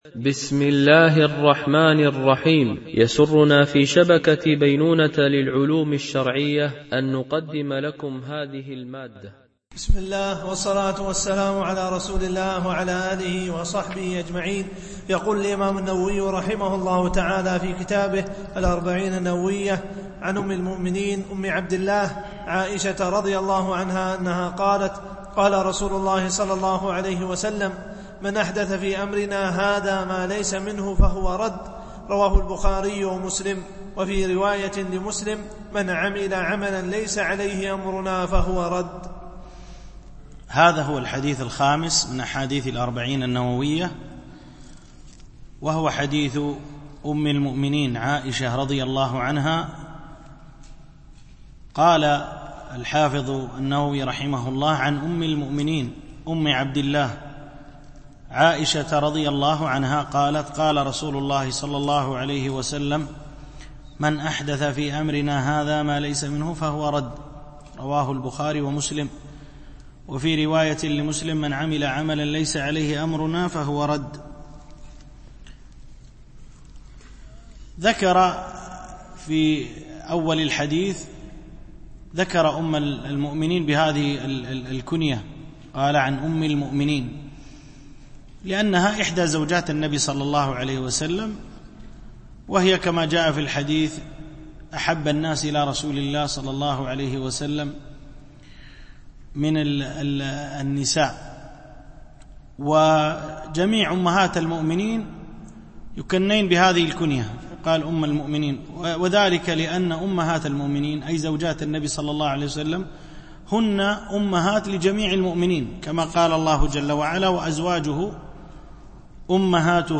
شرح الأربعين النووية - الدرس 4 (الحديث 5-6)